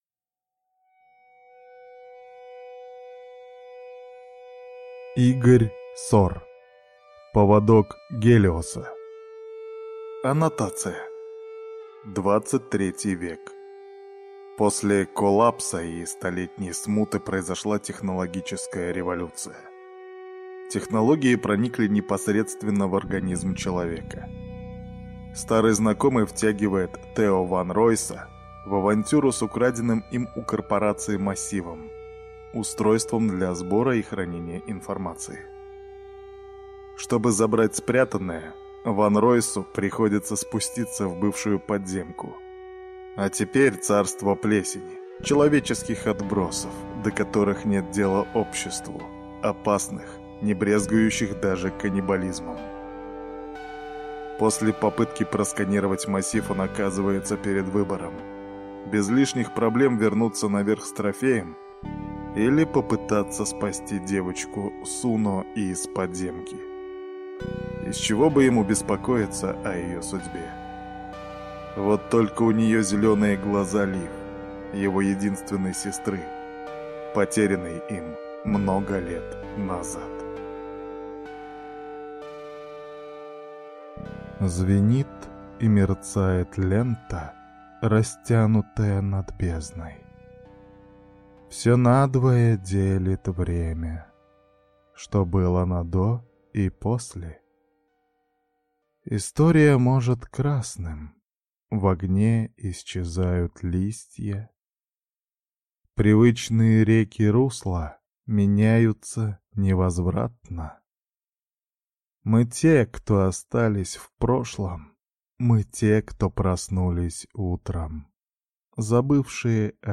Аудиокнига Поводок Гелиоса | Библиотека аудиокниг